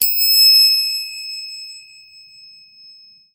自転車の魔法使いが軽快な指揮を振るい、空中に響き渡る旋律。その魔法の音色は、まるで春風に乗ってやってくる笑顔のメロディ。